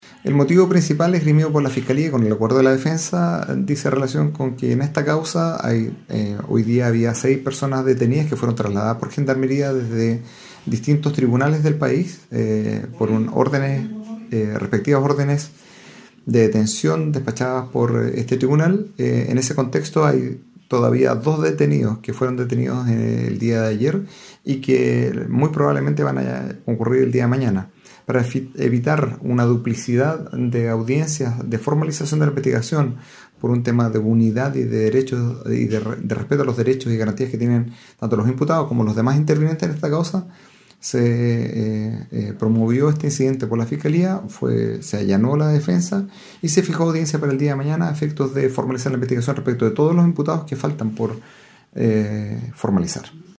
EL Juez del Tribunal de Garantía de Valdivia, Pablo Yáñez, explicó que para evitar una duplicidad de audiencias, se accedió a la petición de la Fiscalía para formalizar este miércoles a las seis personas a quienes se les controló la detención este miércoles, a otro que fue detenido en Santiago y al octavo integrante de la banda apresado en Chillán.